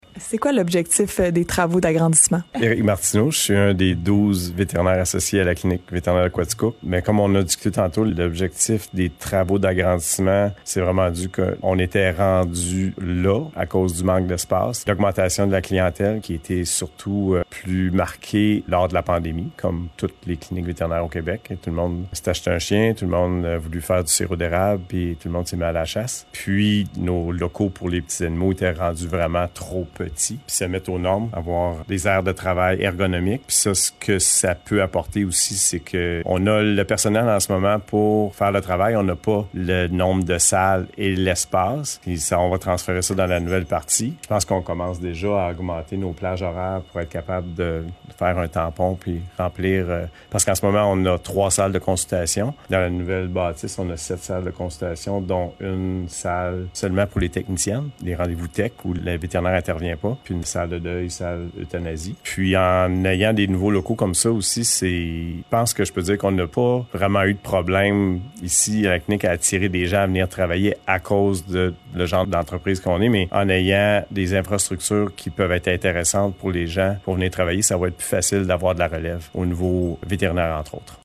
ENTREVUE-2.1.3-CLINIQUE-VET_01.mp3